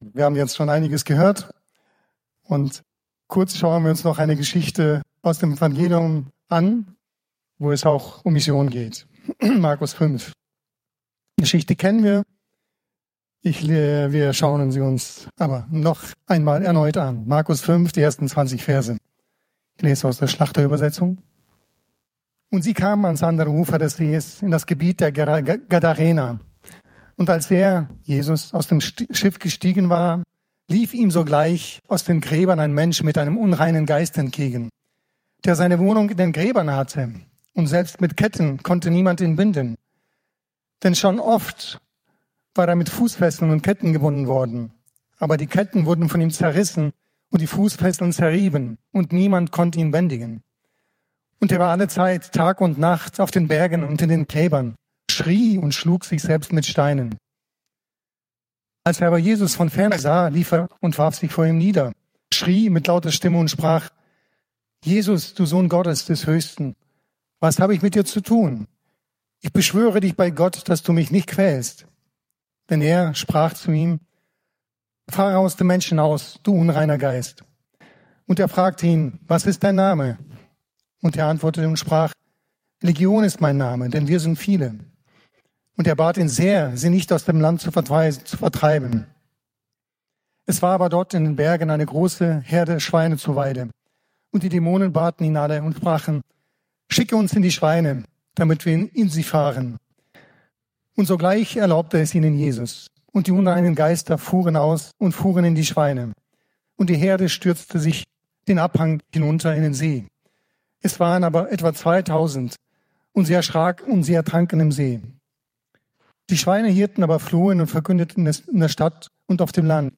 Predigt
im Christlichen Zentrum Villingen-Schwenningen